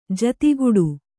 ♪ jatiguḍu